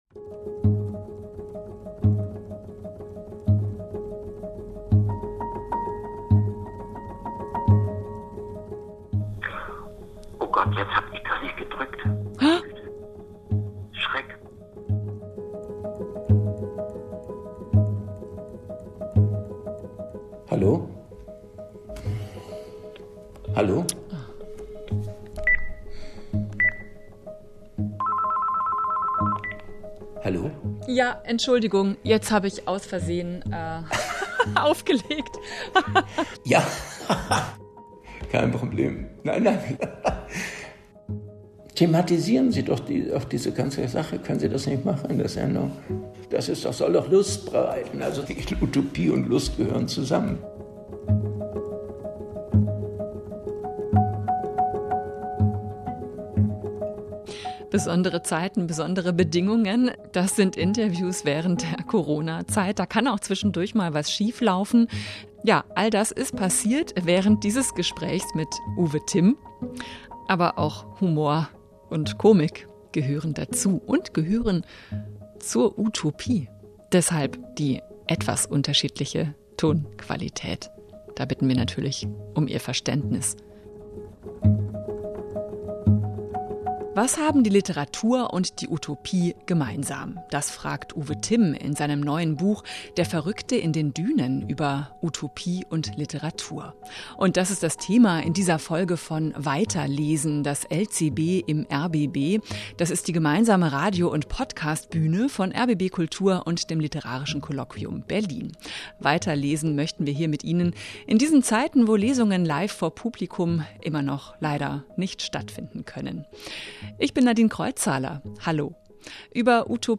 weiter lesen - das LCB im rbb - Dorothee Elmiger liest "Aus der Zuckerfabrik"